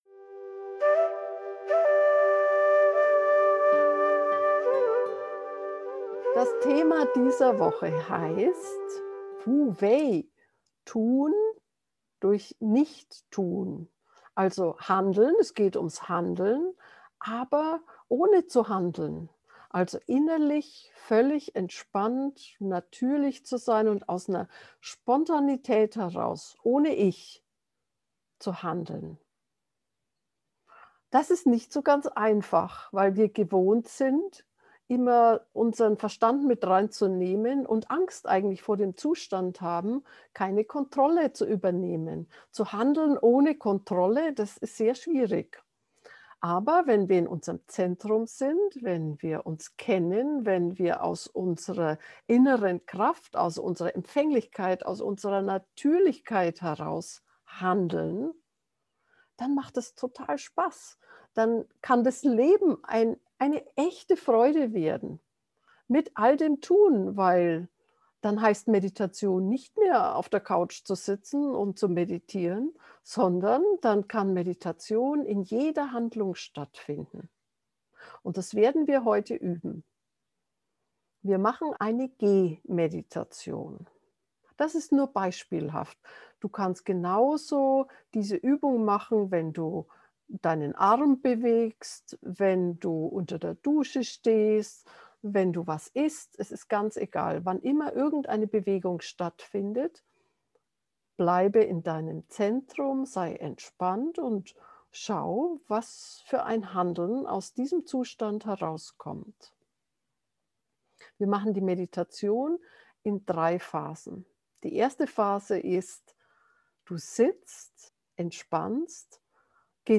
Meditationsanleitung zur geführten Meditation